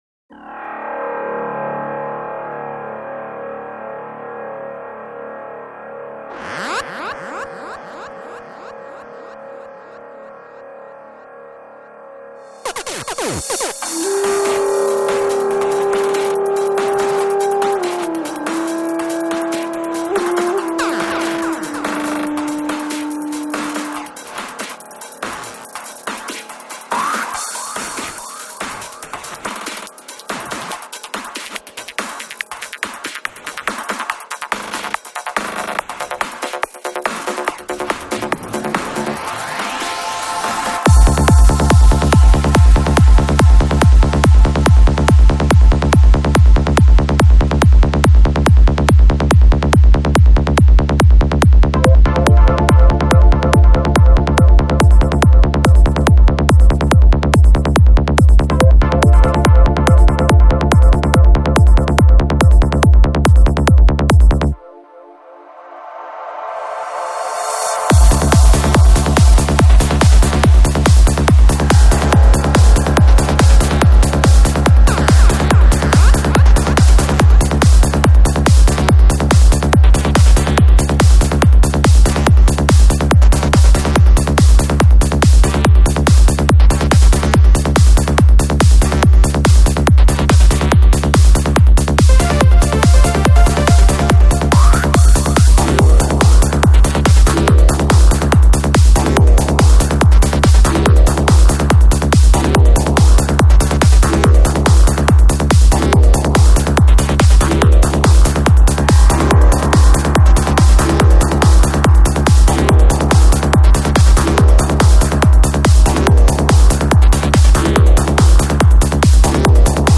Жанр: Trance
Альбом: Psy-Trance